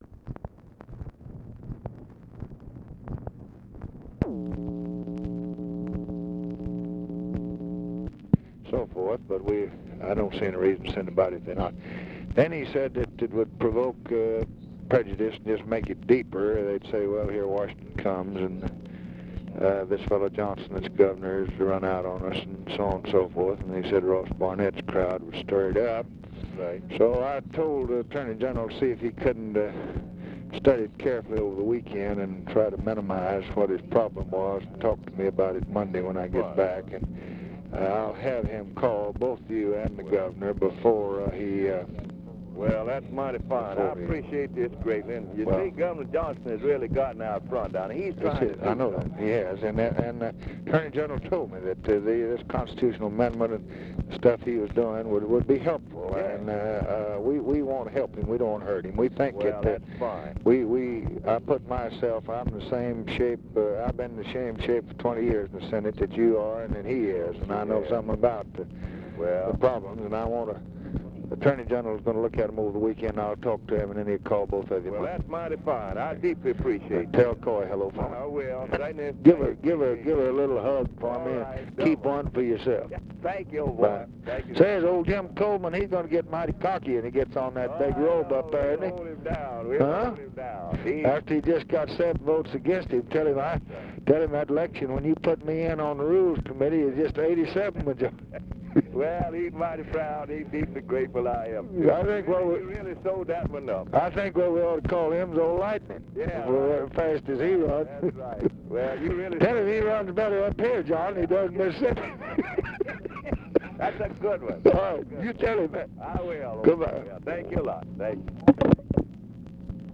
Conversation with JOHN STENNIS, August 6, 1965
Secret White House Tapes